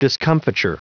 Prononciation du mot discomfiture en anglais (fichier audio)
Prononciation du mot : discomfiture